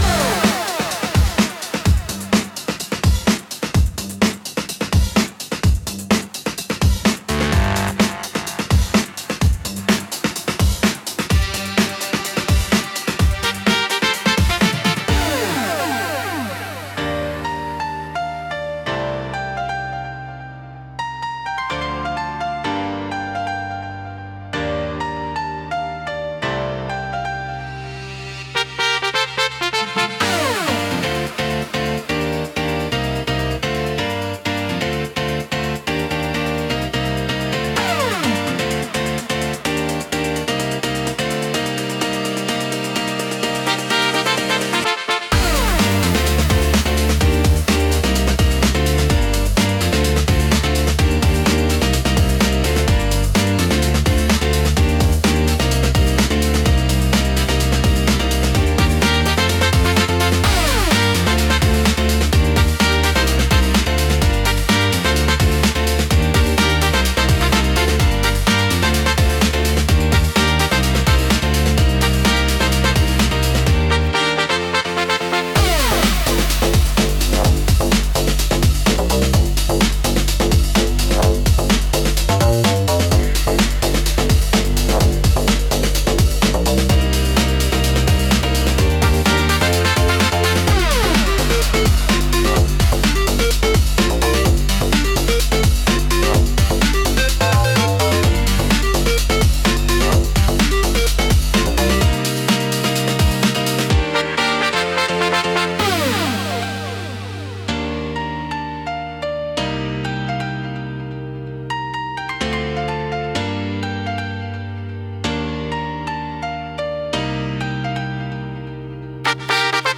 躍動感を生み出し、観客やプレイヤーのテンションを上げる効果が高く、飽きさせないペースで強いインパクトを与えます。